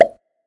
描述：嘴里砰的一声。
Tag: 口腔 随机